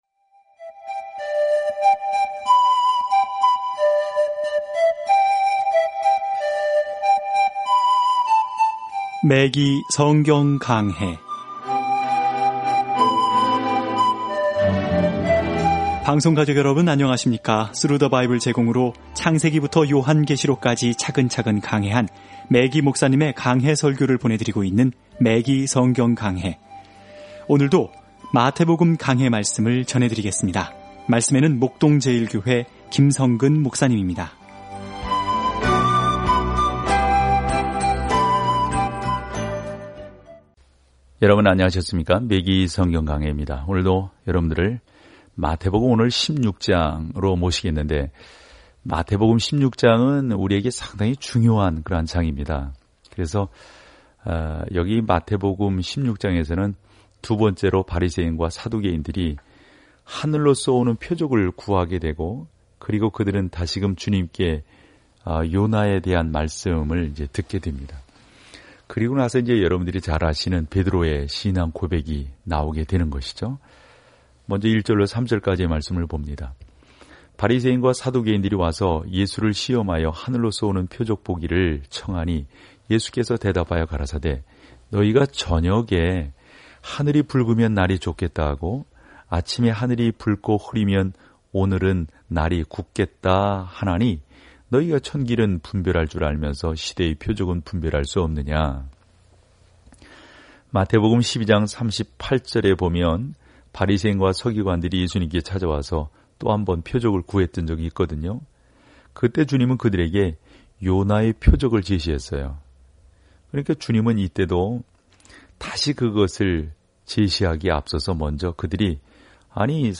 말씀 마태복음 16:1-18 37 묵상 계획 시작 39 묵상 소개 마태는 예수님의 삶과 사역이 어떻게 구약의 예언을 성취했는지 보여줌으로써 예수님이 그들의 메시야라는 좋은 소식을 유대인 독자들에게 증명합니다. 오디오 공부를 듣고 하나님의 말씀에서 선택한 구절을 읽으면서 매일 마태복음을 여행하세요.